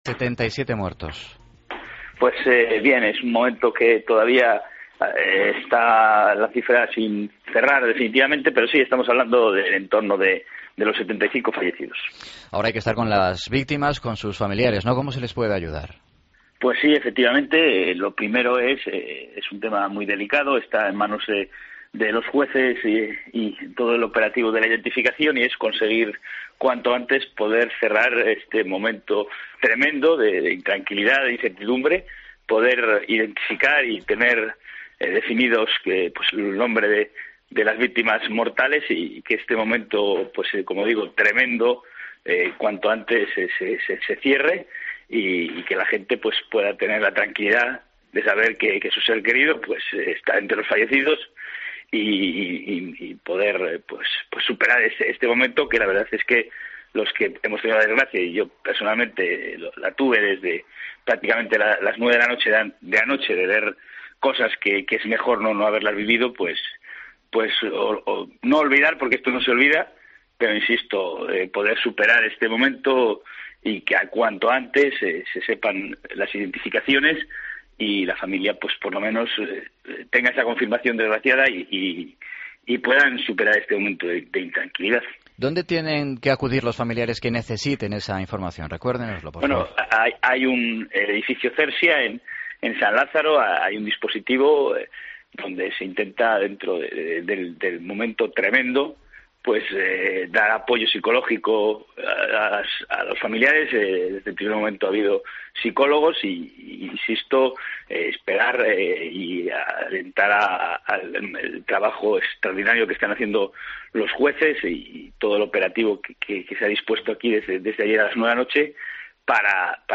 Contiene la entrevista a Agustín Hernández, consejero de Medio Ambiente, Territorio e Infraestructuras de Galicia.